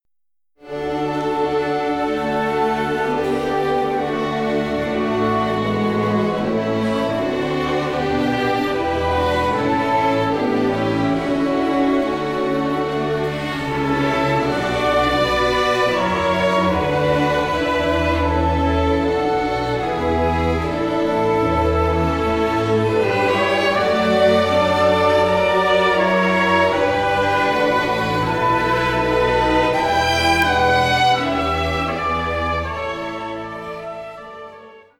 • kurzweilige Zusammenstellung verschiedener Live-Aufnahmen
Orchester